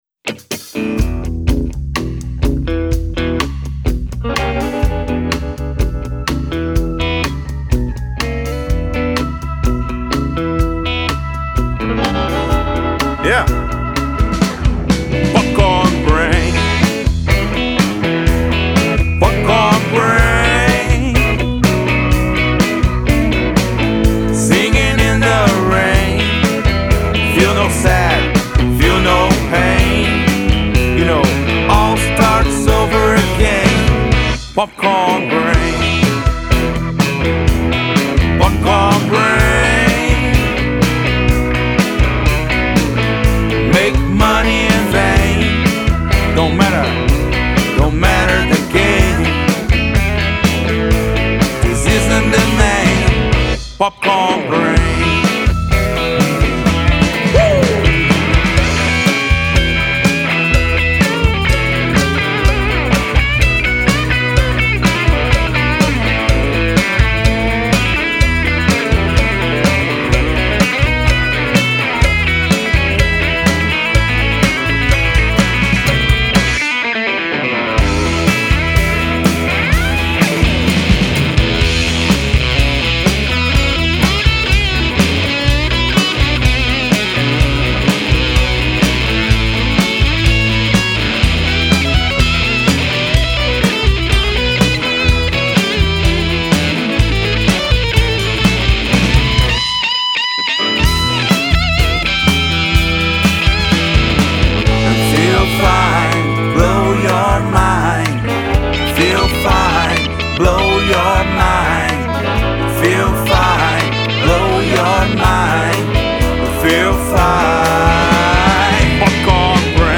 2262   03:55:00   Faixa:     Jazz